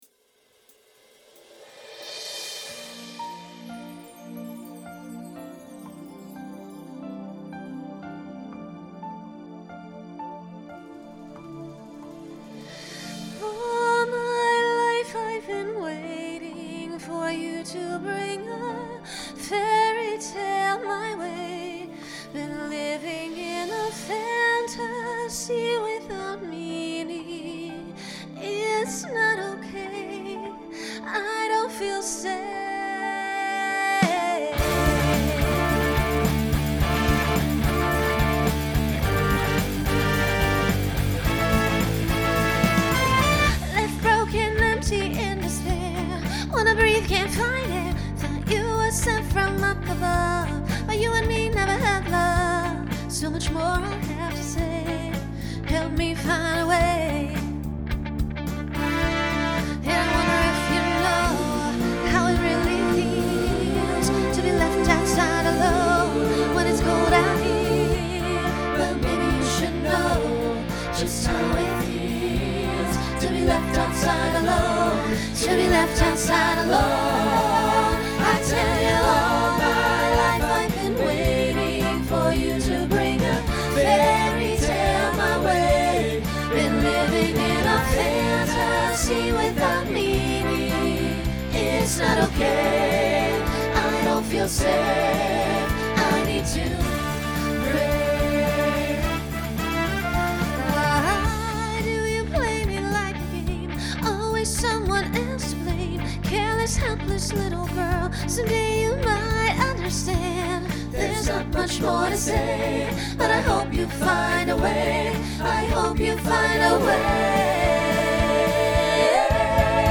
Genre Pop/Dance
Transition Voicing SATB